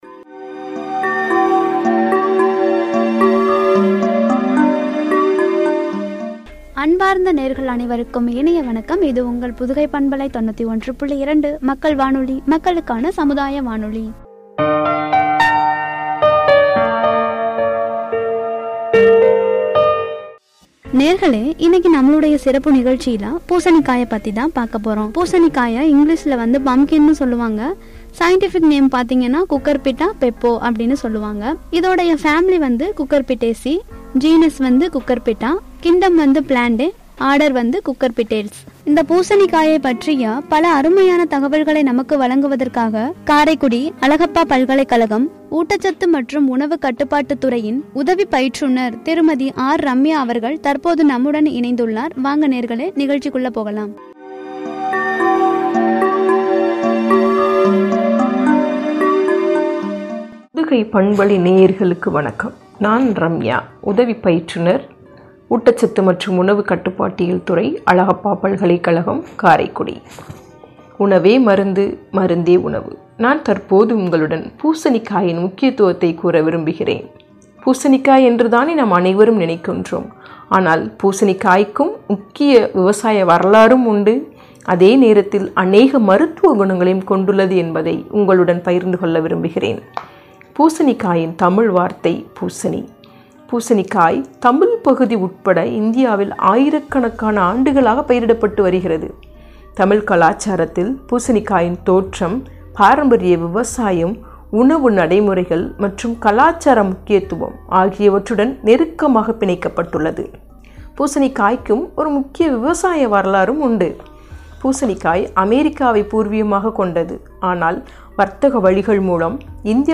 “நலம் தரும் பூசணிக்காய்” என்ற தலைப்பில் வழங்கிய உரை.